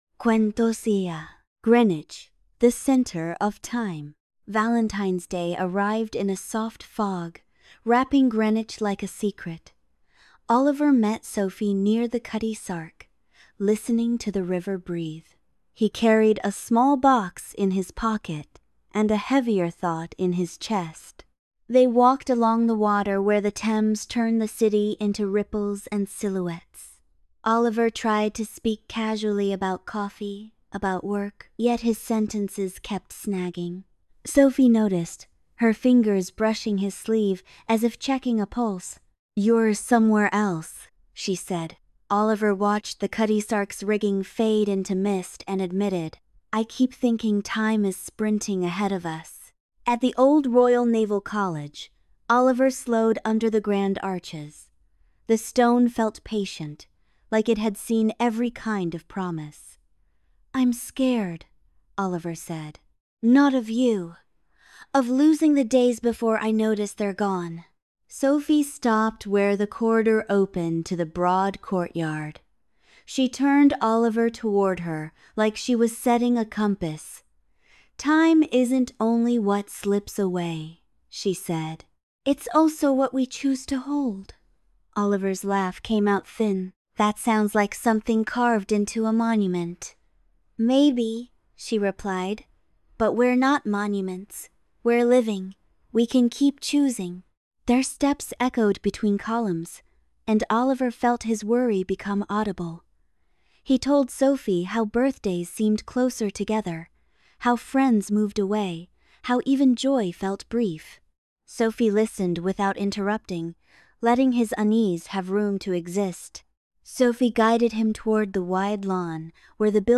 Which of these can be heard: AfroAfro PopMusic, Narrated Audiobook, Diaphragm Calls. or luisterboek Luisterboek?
Narrated Audiobook